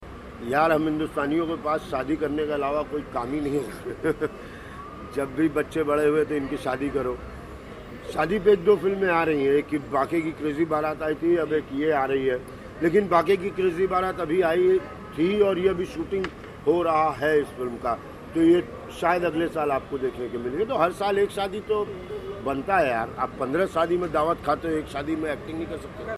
अपनी आने वाली फ़िल्म ‘शादी अभी बाक़ी है’ पर बात कर रहे हैं अभिनेता संजय मिश्रा.